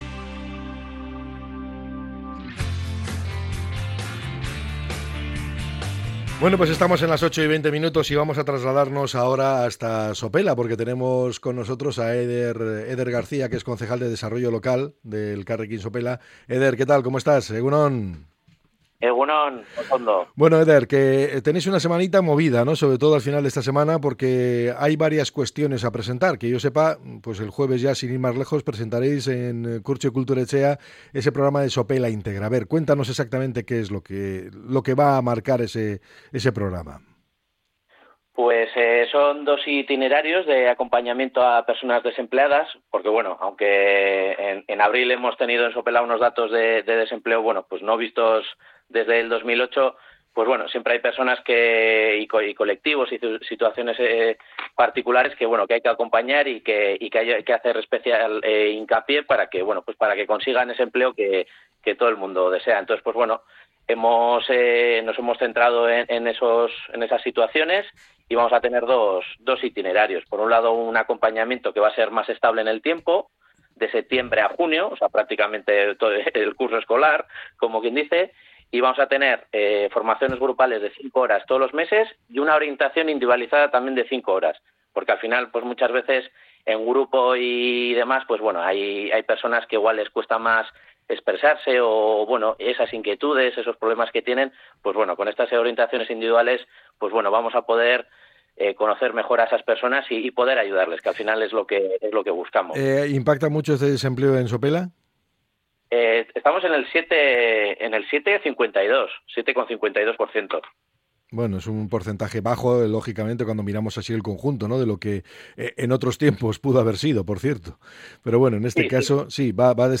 El concejal de Desarrollo Local del Ayuntamiento de Sopela por Elkarrekin Sopela, Eder García, ha detallado en una entrevista en el programa EgunOn Bizkaia de Radio Popular – Herri Irratia las nuevas acciones municipales para fomentar el empleo en el municipio.